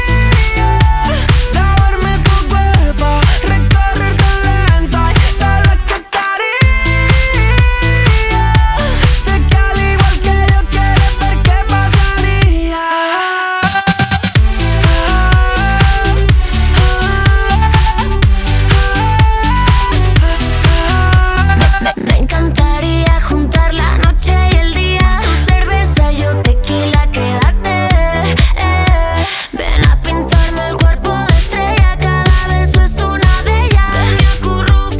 Жанр: Поп
# Pop in Spanish